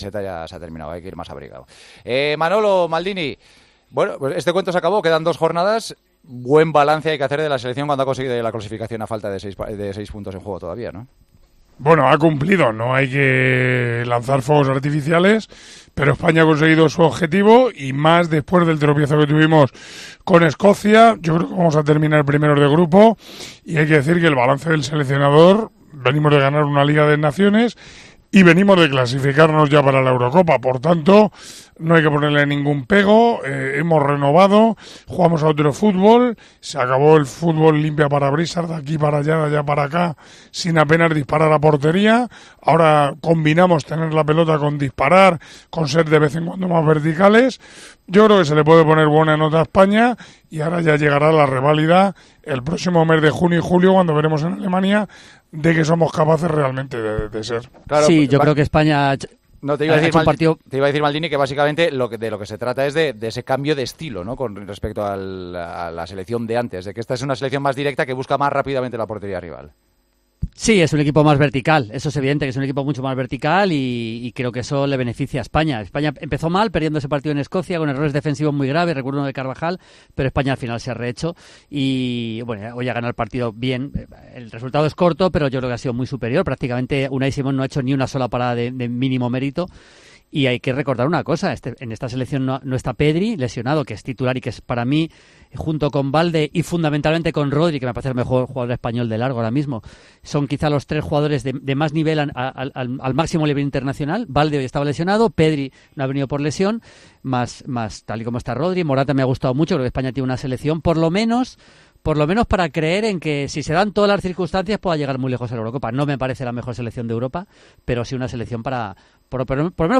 El narrador de los partidos del Real Madrid y de España en Tiempo de Juego valoró junto a Maldini el partido de España contra Noruega
"Ha cumplido, pero no hay que lanzar fuegos artificiales", fueron las primeras palabras de Manolo Lama analizando la clasificación de España durante la retransmisión de Tiempo de Juego.